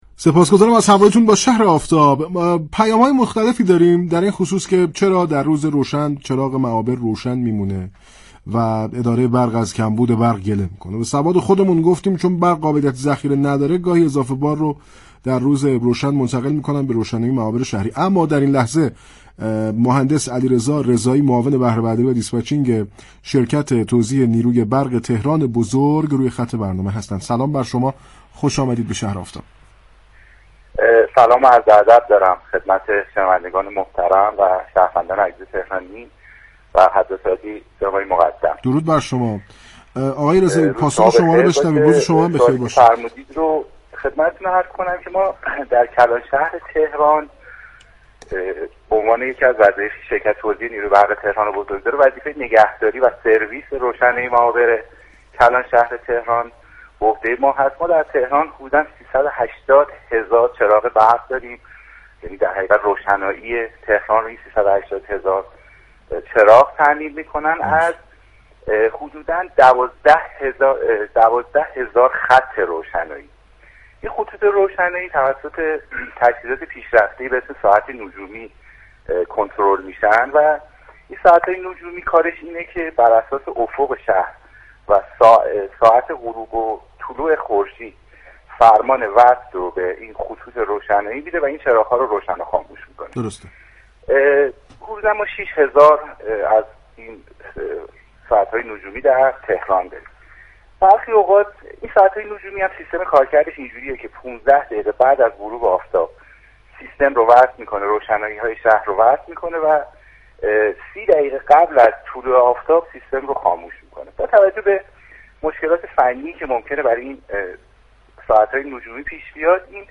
در گفت و گو با "شهر آفتاب" رادیو تهران